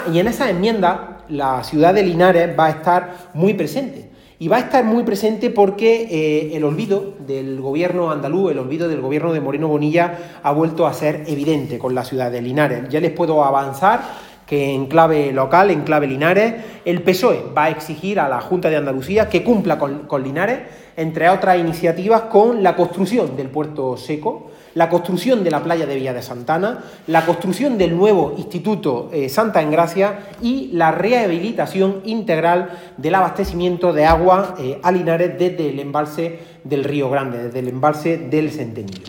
En rueda de prensa en Linares, tras una reunión del Grupo Parlamentario Socialista,
Cortes de sonido